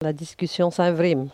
parole, oralité